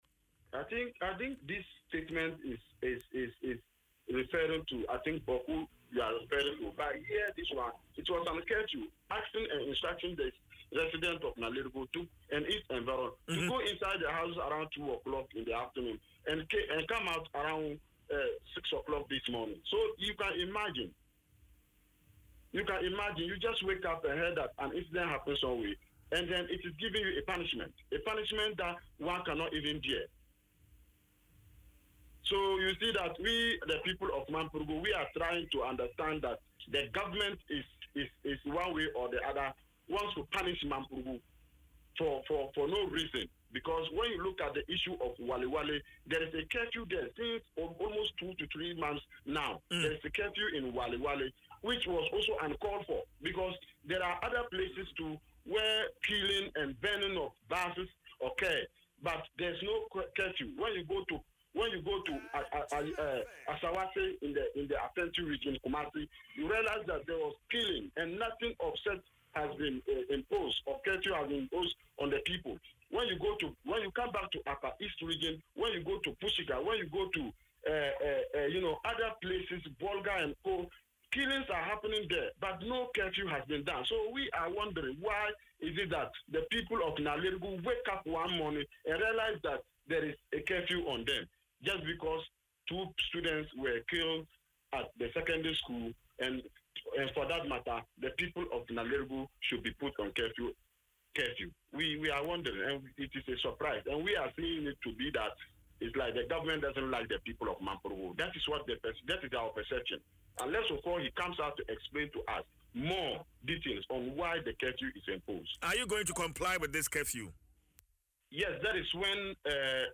Speaking on Adom FM’s Dwaso Nsem, Mr. Fuseini described the curfew as unfair and counterproductive, arguing that it punishes residents rather than addressing the root causes of the security crisis.
Bukari-Fuseini-on-Nalerigu-curfew.mp3